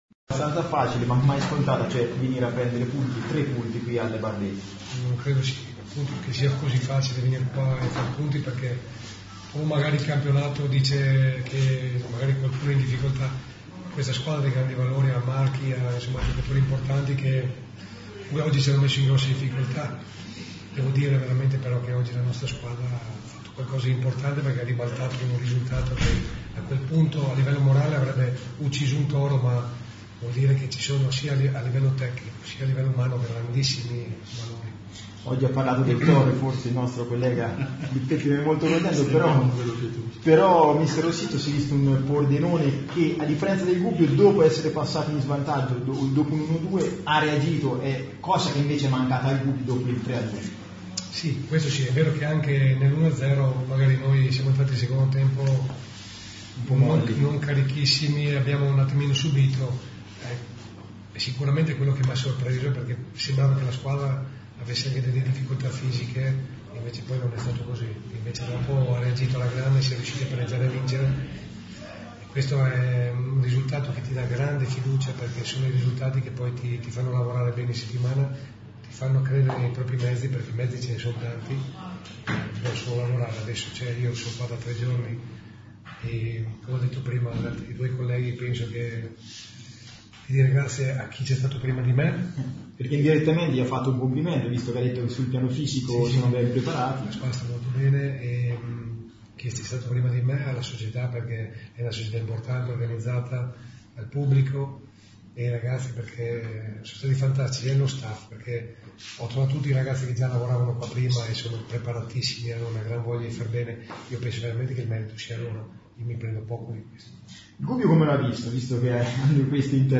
Ecco l’audio delle dichiarazioni del tecnico.